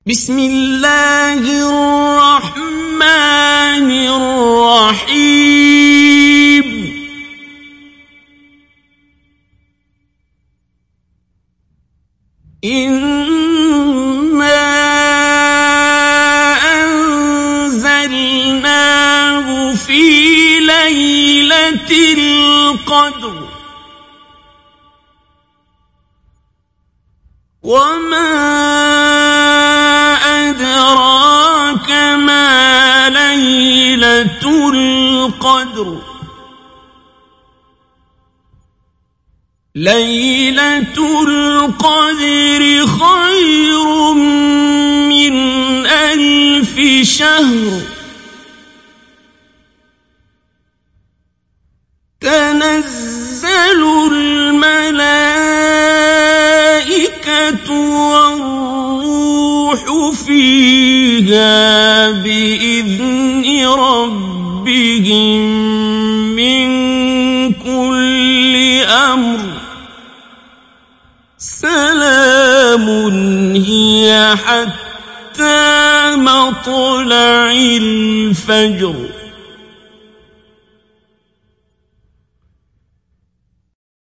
ডাউনলোড সূরা আল-ক্বাদর Abdul Basit Abd Alsamad Mujawwad
Mujawwad